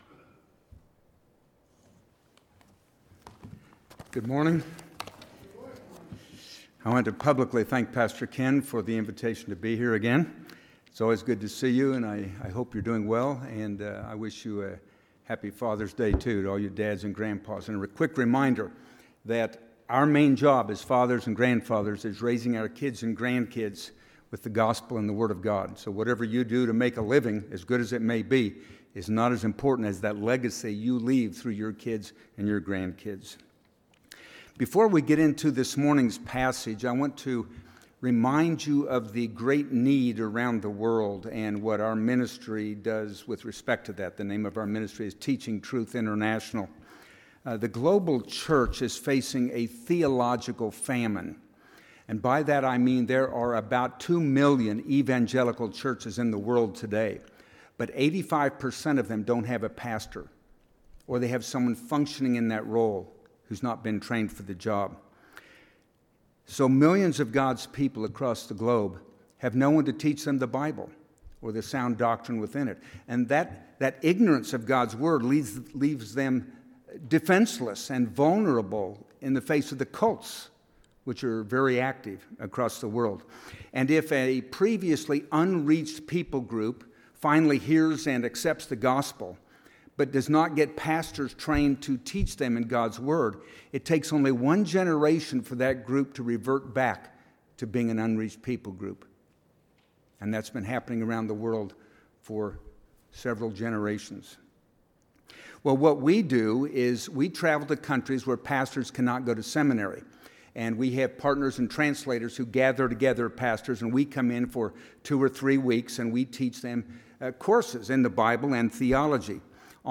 Passage: 2 Peter 1:16-21 Service Type: Sunday AM